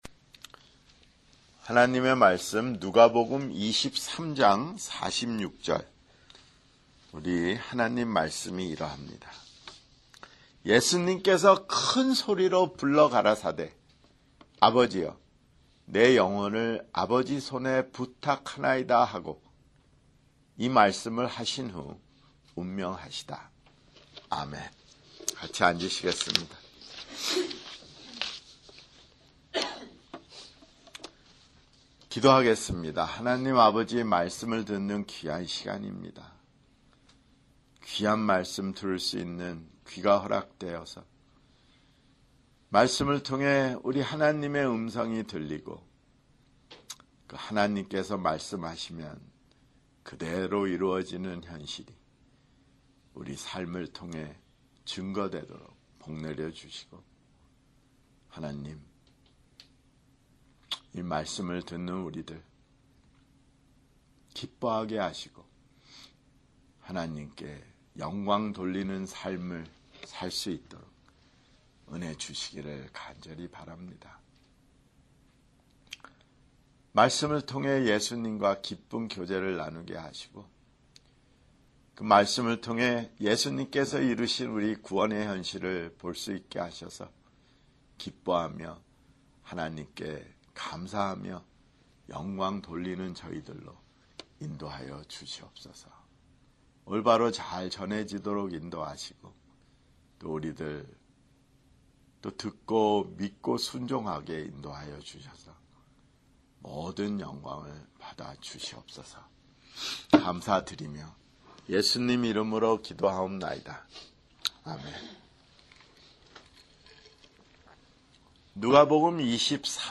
[주일설교] 누가복음 (171)